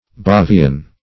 bavian.mp3